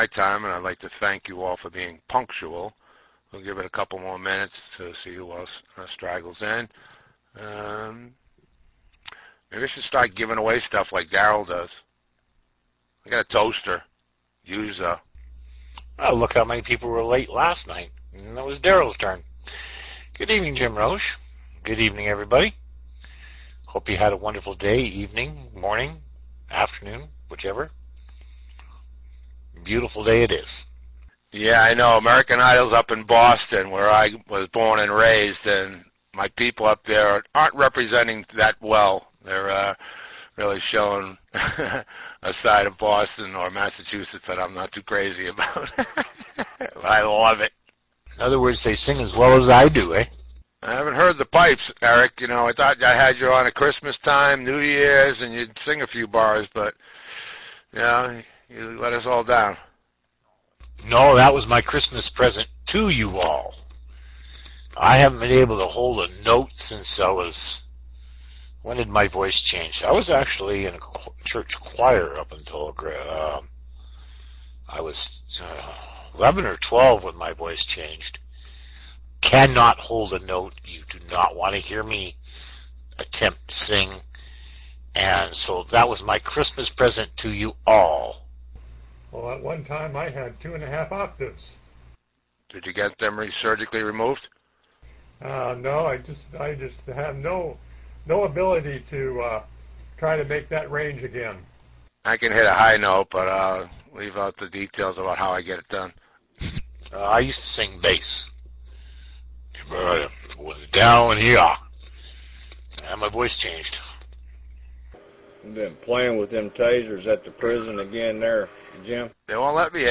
Recordings of 12 second commute conferences. Webinars of training on the use of online business tools in particular the tools supplied by 12 Second Commute.